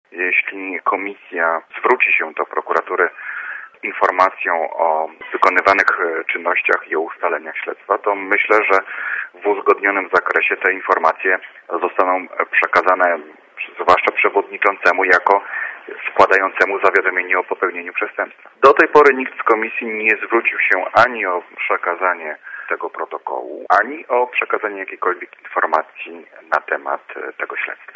Posłuchaj rzecznika prokuratury